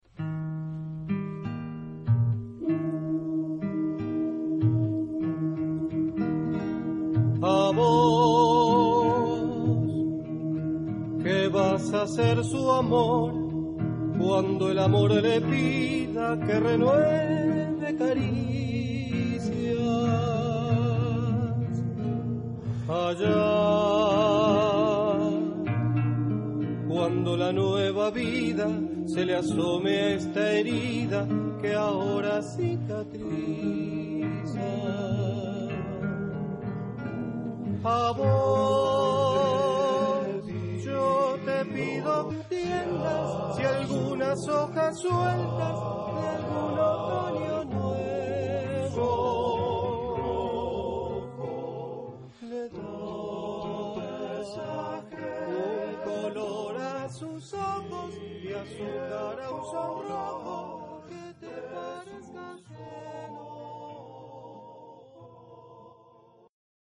Caractère de la pièce : affectueux
Type de choeur : TTBBB  (5 voix mixtes )
Tonalité : tonal